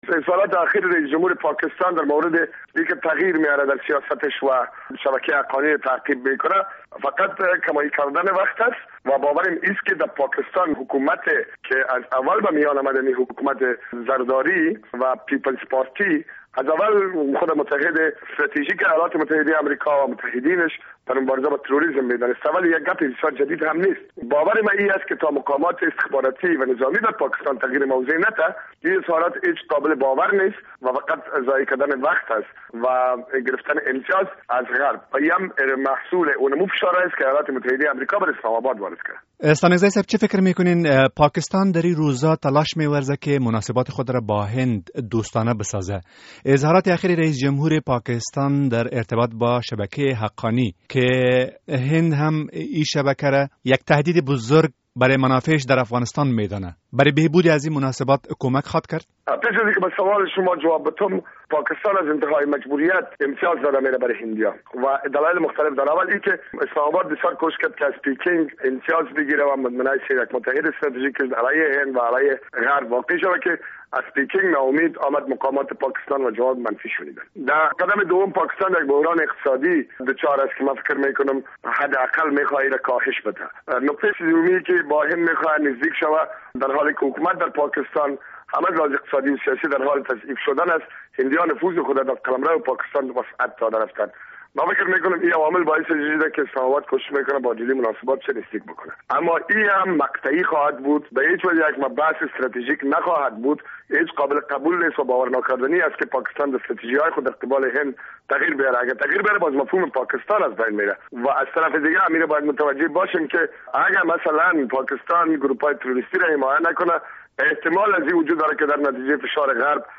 مصاحبه در مورد تعهد رییس جمهور پاکستان روی همکاری با امریکا